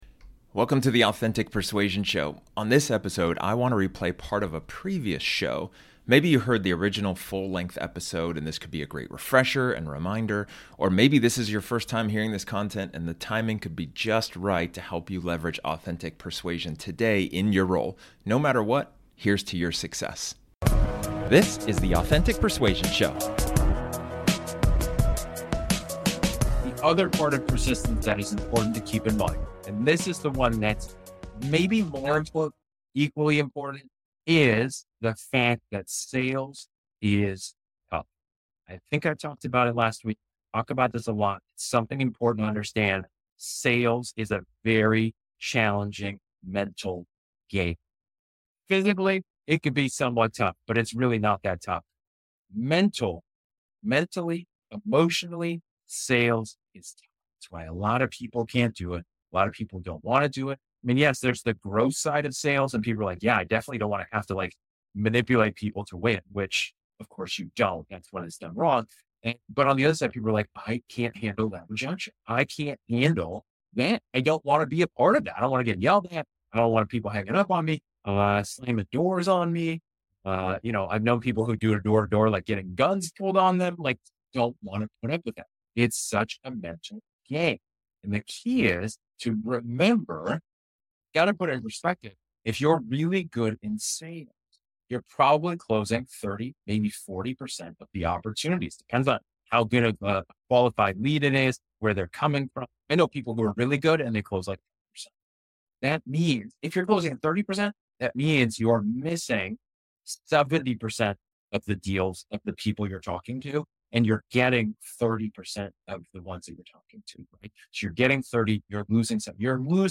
This episode is an excerpt from one of my training sessions where I talk about one of the 5 Sales Success Traits.